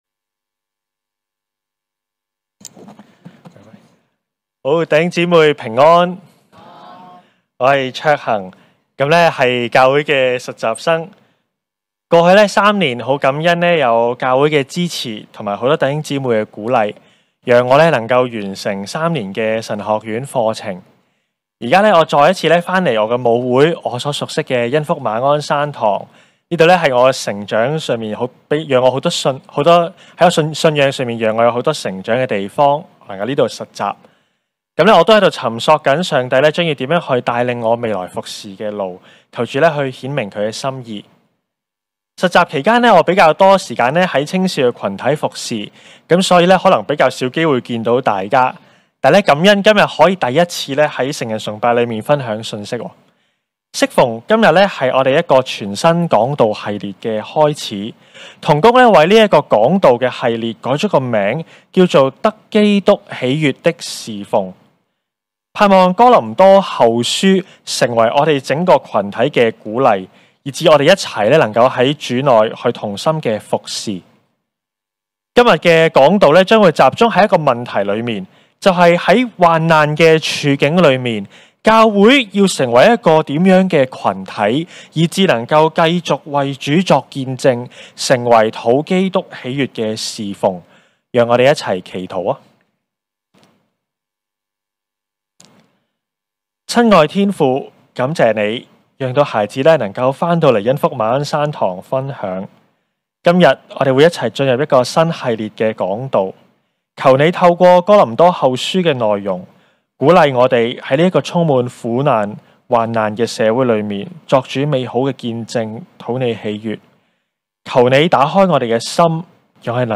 證道重溫
恩福馬鞍山堂崇拜-早、午堂